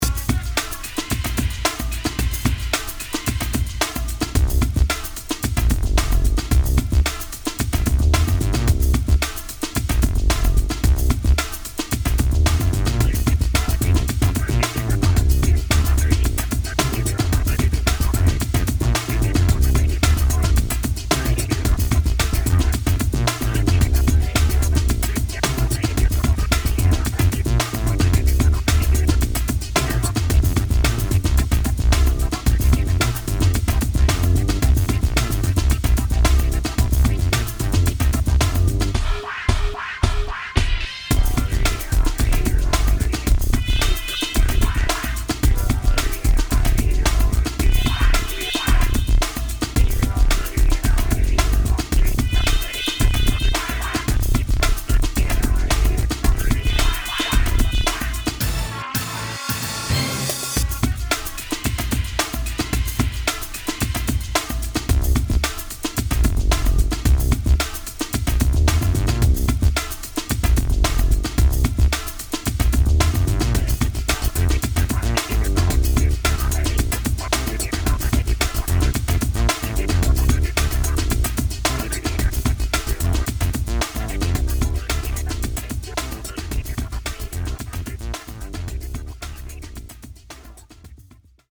ヒップホップ音楽
ゲームミュージック等に用いられ、リズム感があり楽しい感じを演出します。